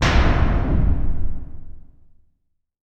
LC IMP SLAM 5B.WAV